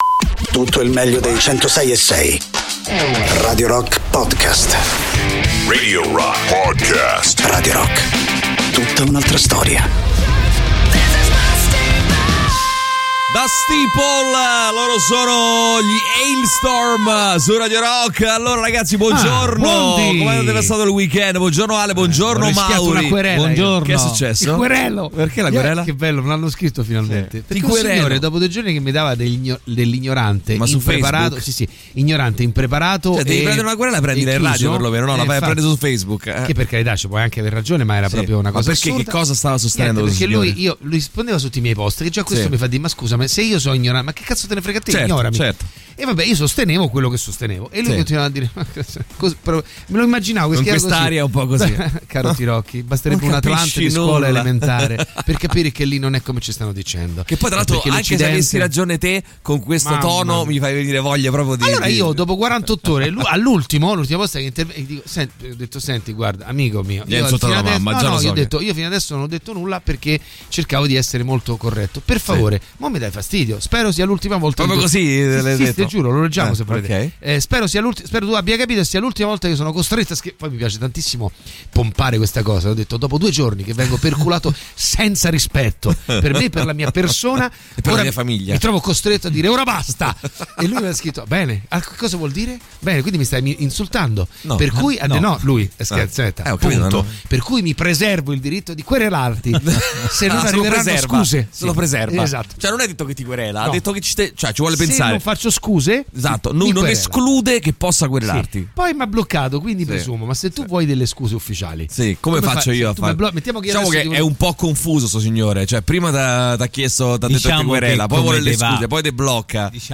in diretta dal lunedì al venerdì dalle 6 alle 10 sui 106.6 di Radio Rock